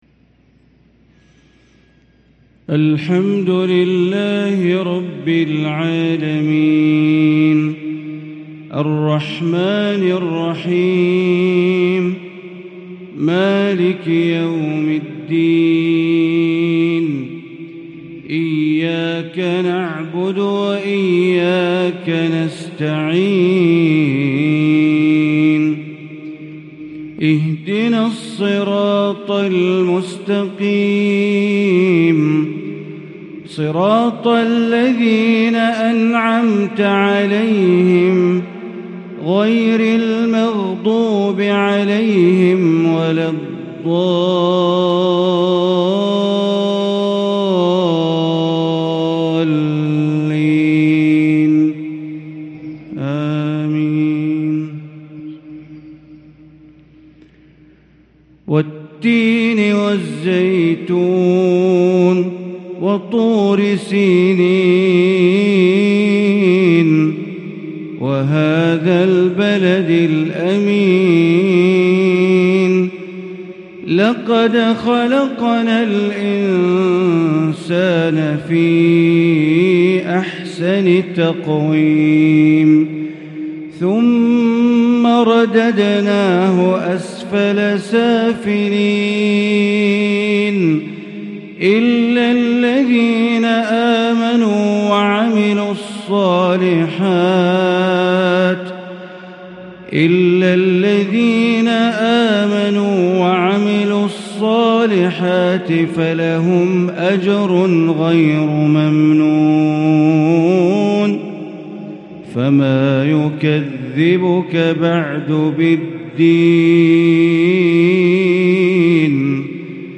صلاة المغرب ٨ محرم ١٤٤٤هـ سورتي التين و القدر | Maghrib prayer from Surah at-Tin & al-qadr 6-8-2022 > 1444 🕋 > الفروض - تلاوات الحرمين